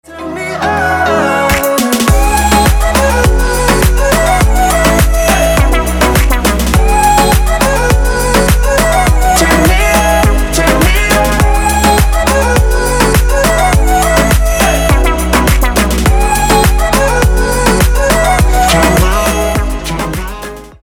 • Качество: 320, Stereo
dance
Electronic
club